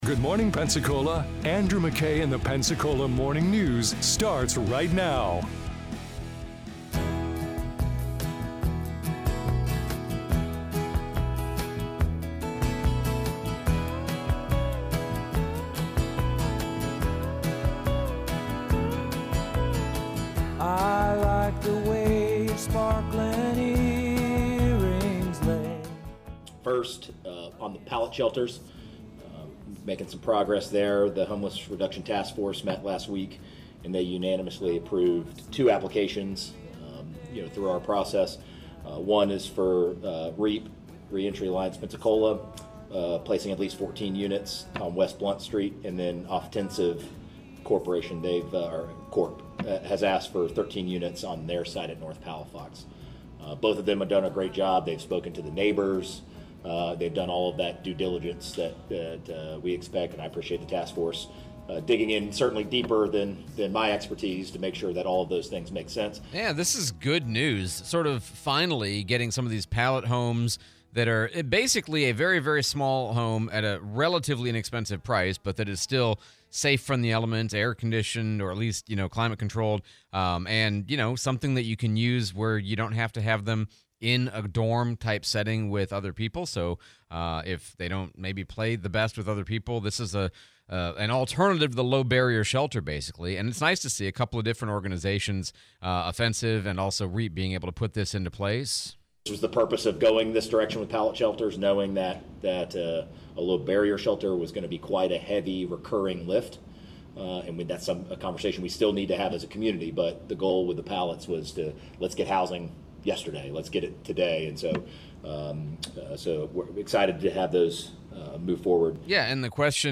Pallet Shelters, Replay of Sheriff Chip Simmons interview